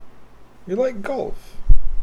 golf-rising-intonation.mp3